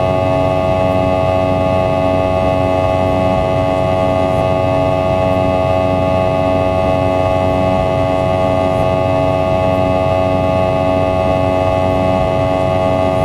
Add IAE cockpit sounds
v2500-buzz.wav